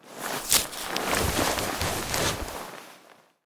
bandage_use.ogg